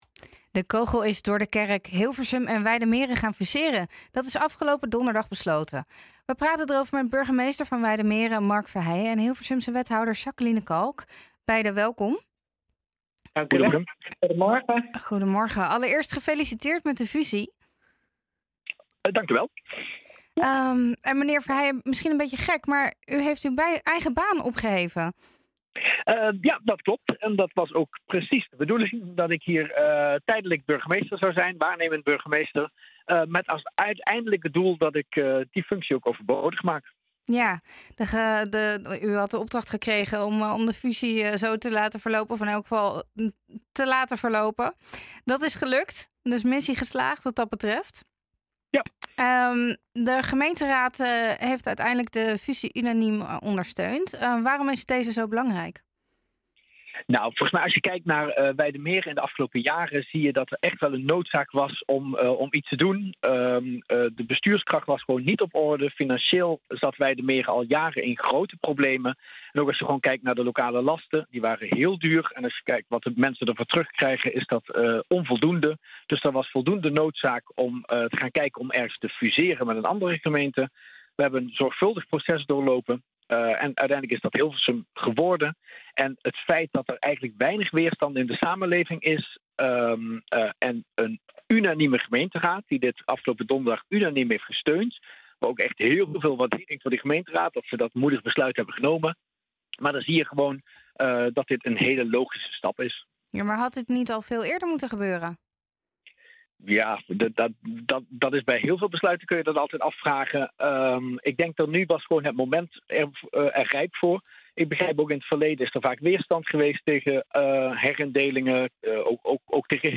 We gaan het erover hebben met burgemeester van Wijdemeren Mark Verheijen en Hilversumse wethouder Jacqueline Kalk.